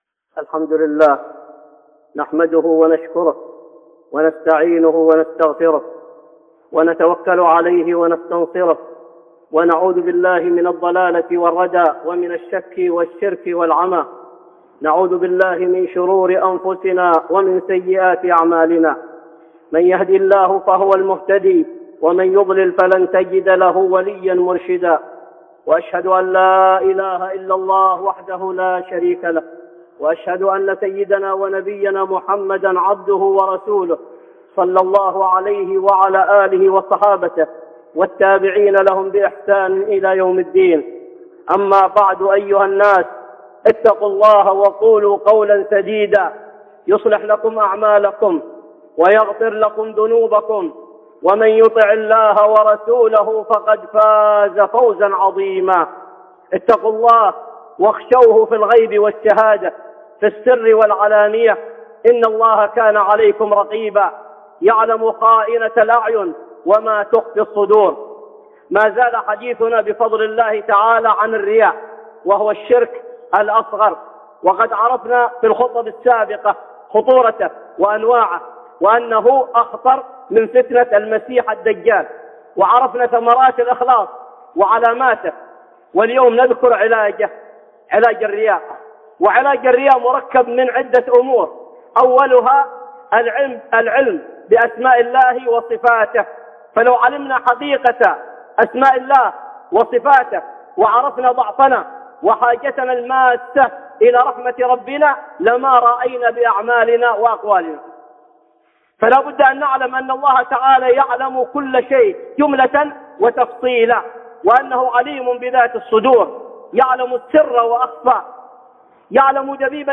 (خطبة جمعة) علاج الرياء